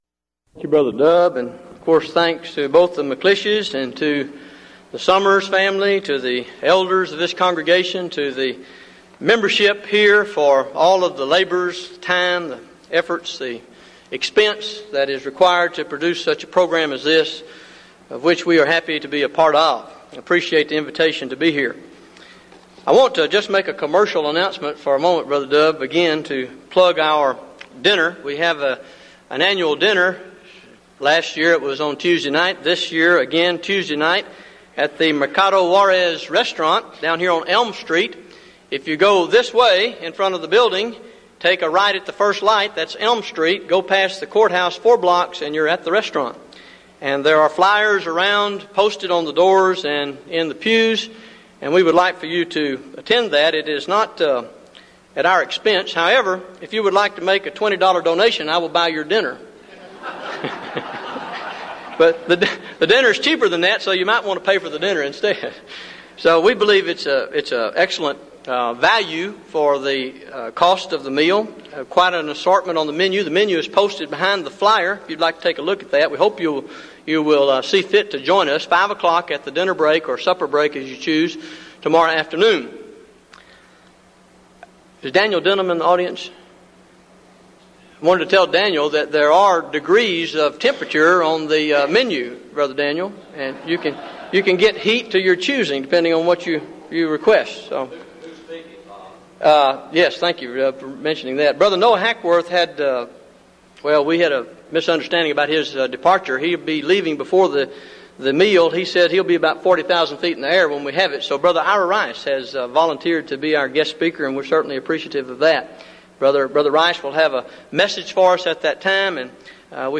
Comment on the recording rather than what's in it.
Event: 1998 Denton Lectures